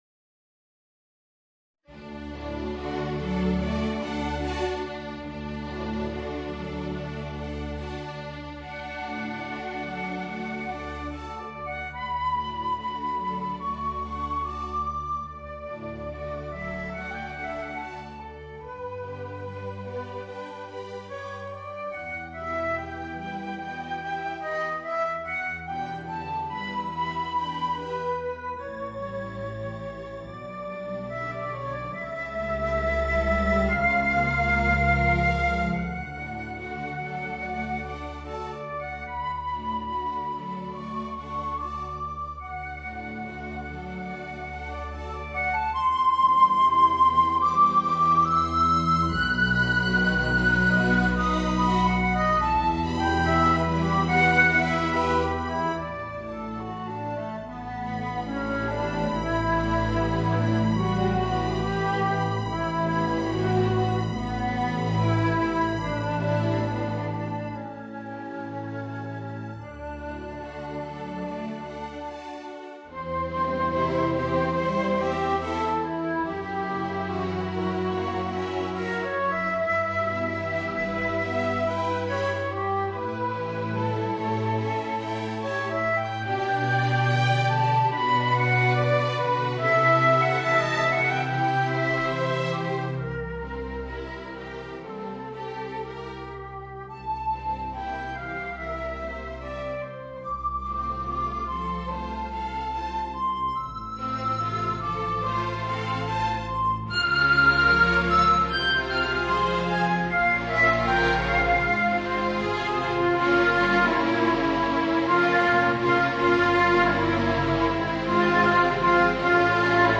Voicing: Viola and String Quintet